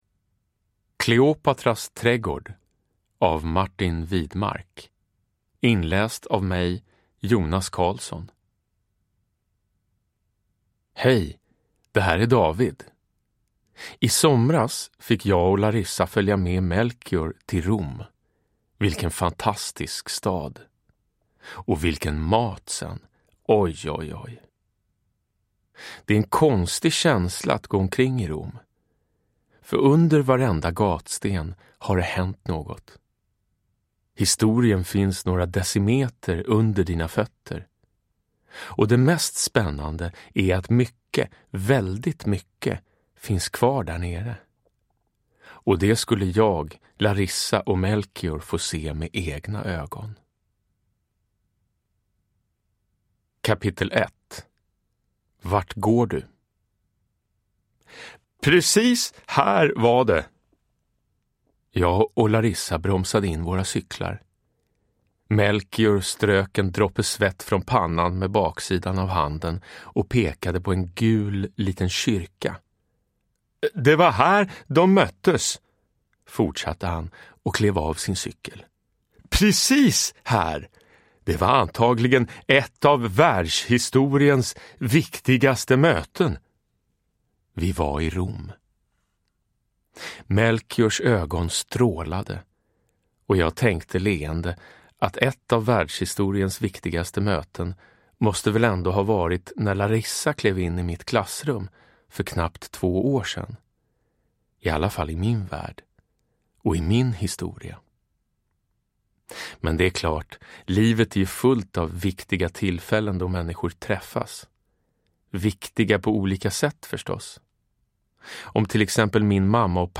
Kleopatras trädgård – Ljudbok – Laddas ner
Uppläsare: Jonas Karlsson